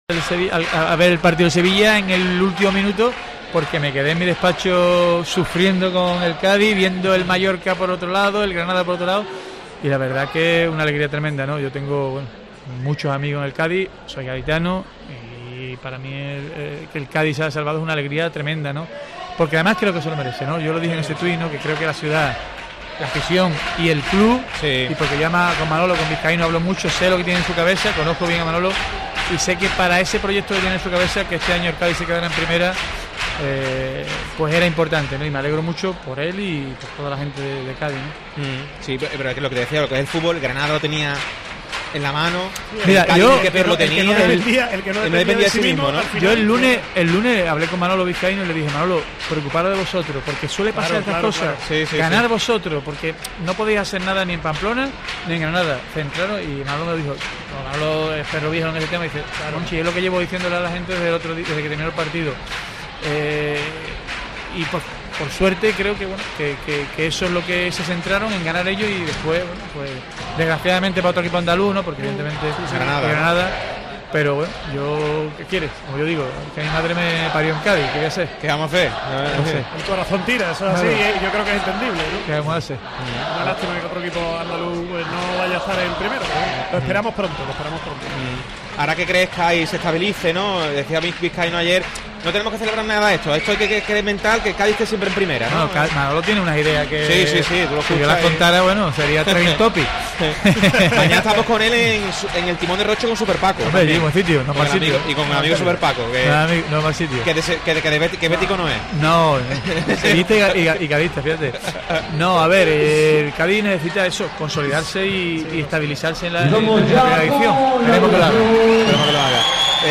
Monchi habla de Carnaval y fútbol en COPE desde el Falla